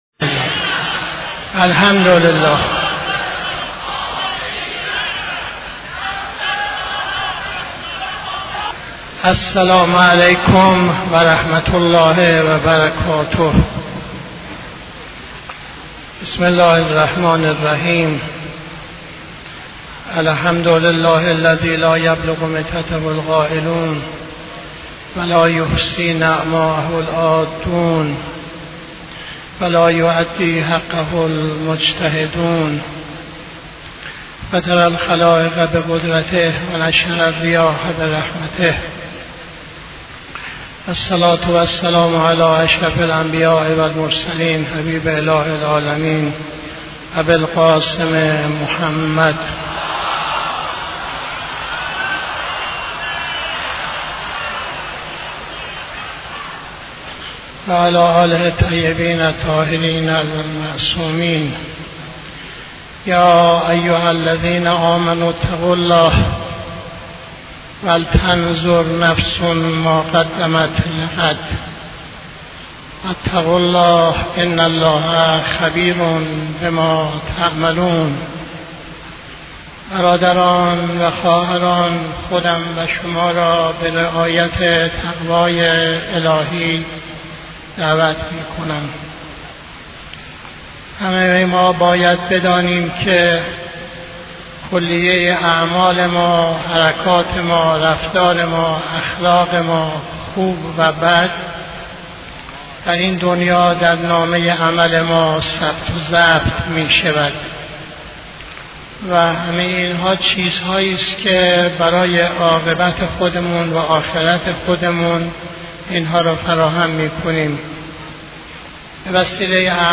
خطبه اول نماز جمعه 07-01-83